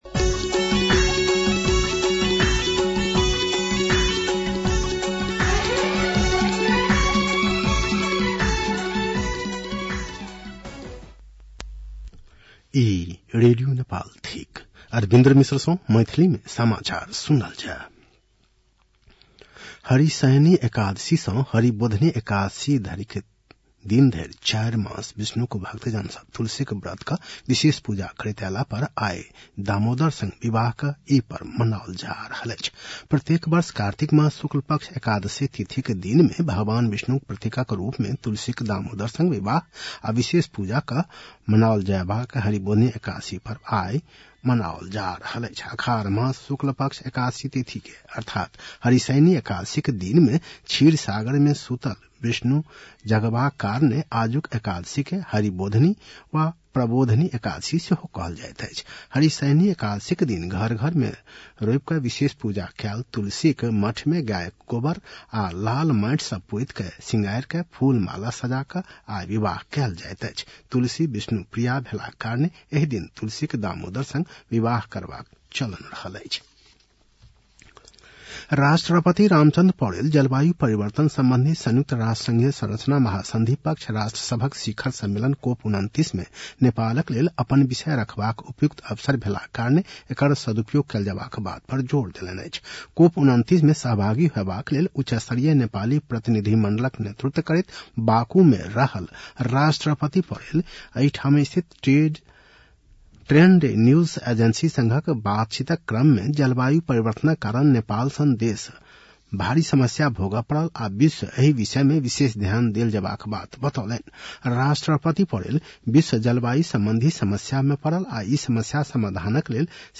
मैथिली भाषामा समाचार : २८ कार्तिक , २०८१